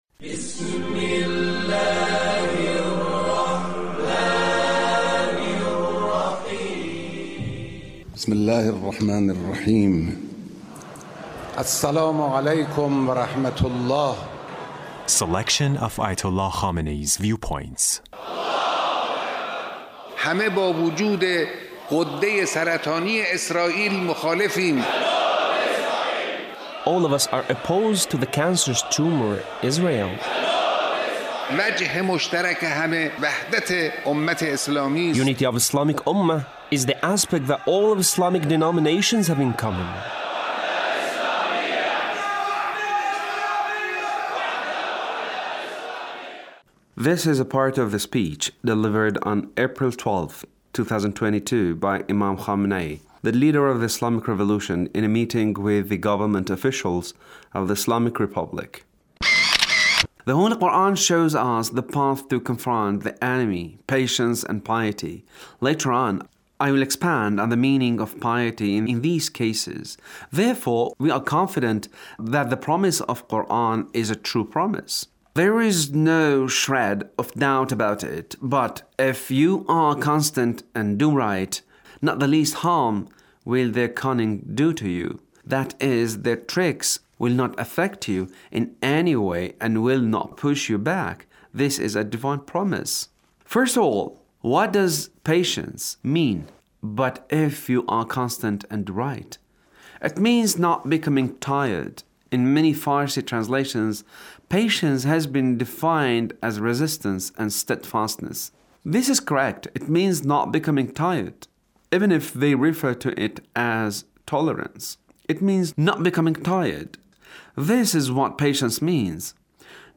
The Leader's speech on Ramadan